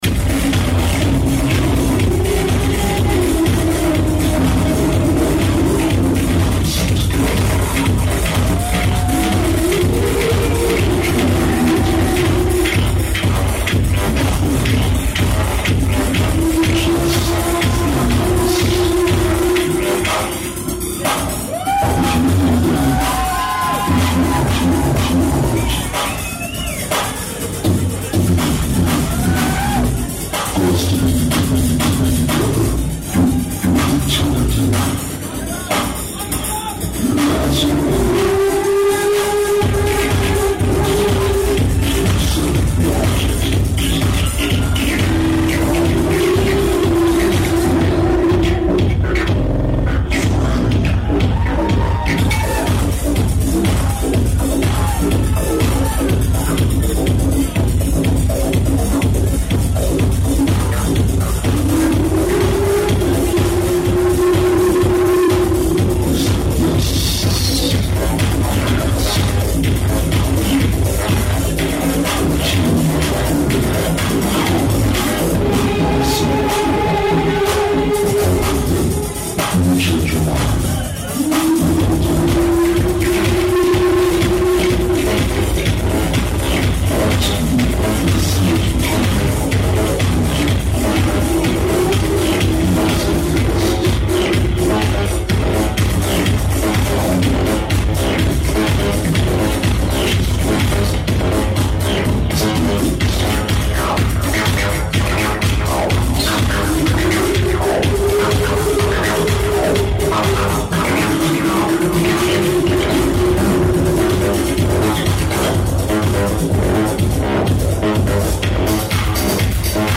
venue Prince Bandroom